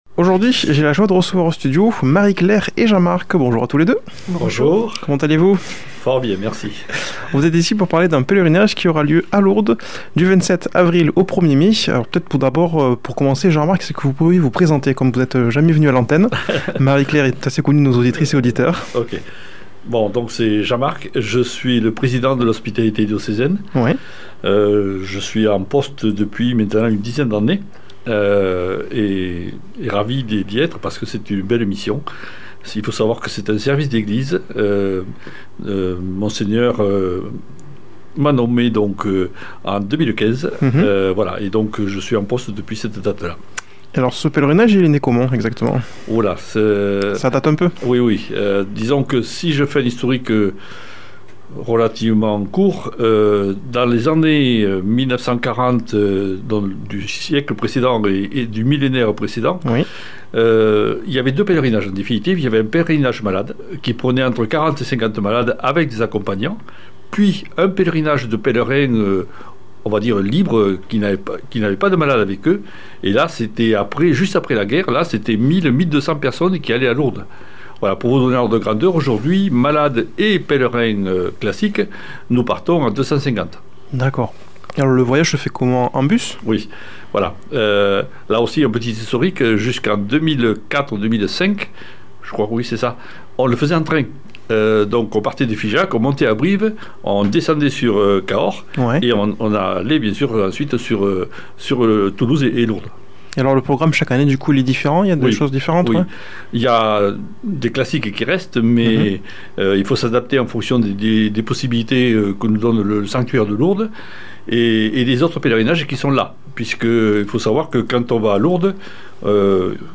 a comme invités au studio